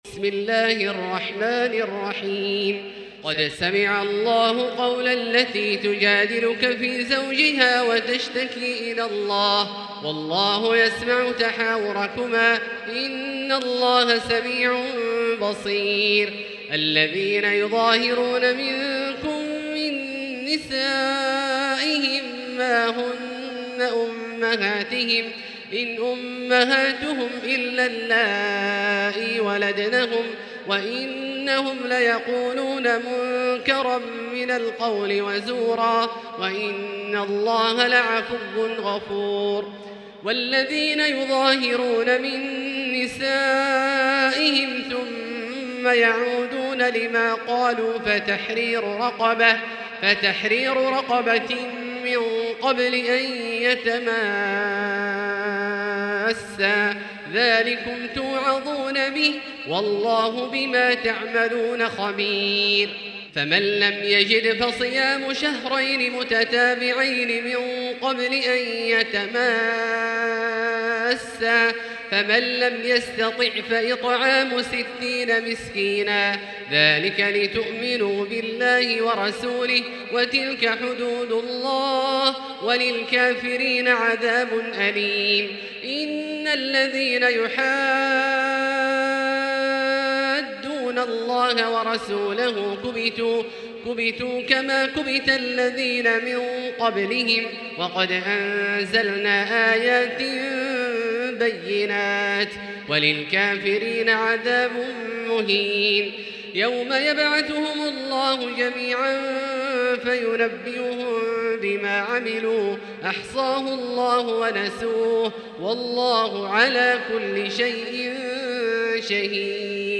المكان: المسجد الحرام الشيخ: فضيلة الشيخ عبدالله الجهني فضيلة الشيخ عبدالله الجهني المجادلة